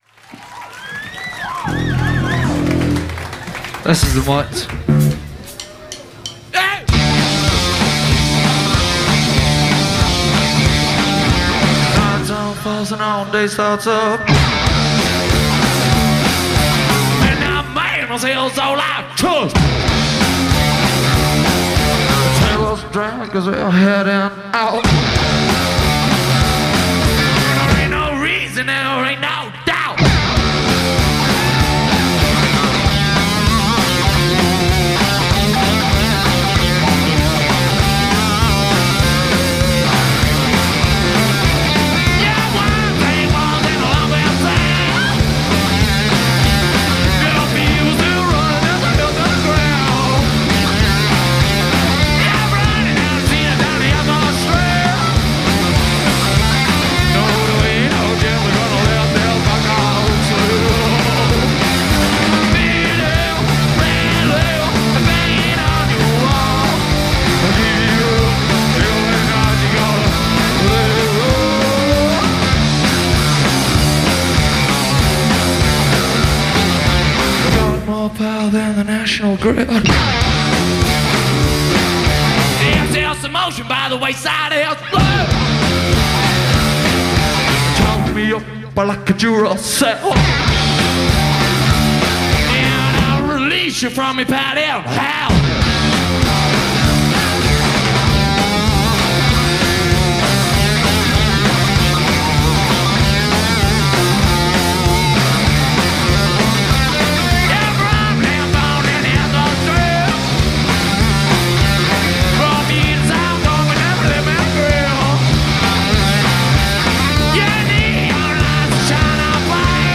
Live from The Brighton Concorde
scuzzy rock band